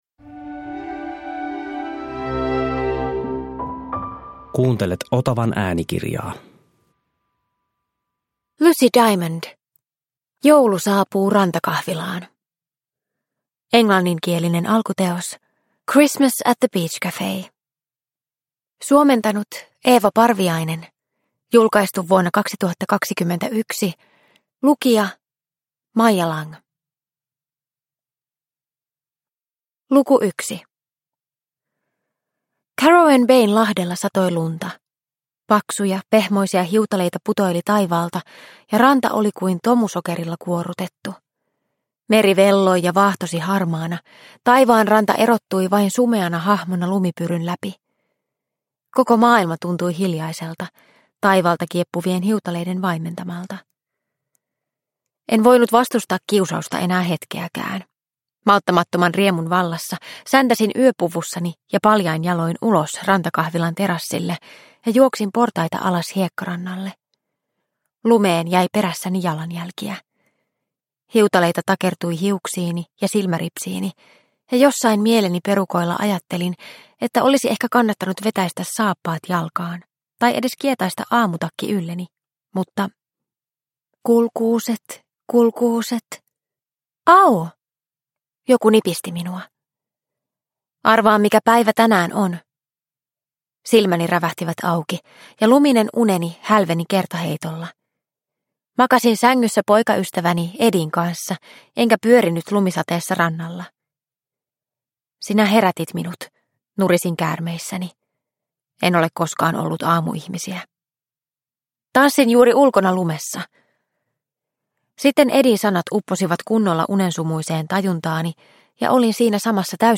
Joulu saapuu Rantakahvilaan – Ljudbok – Laddas ner